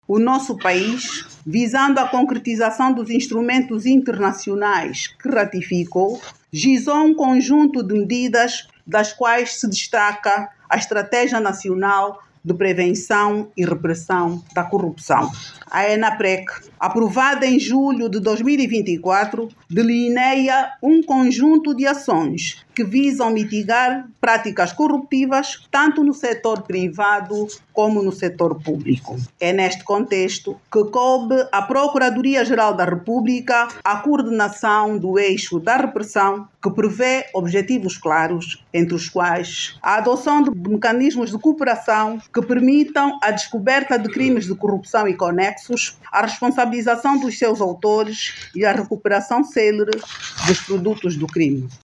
Inocência Pinto falava durante o acto que marcou mais um aniversário da Convenção.
INOCENCIA-PINTO.mp3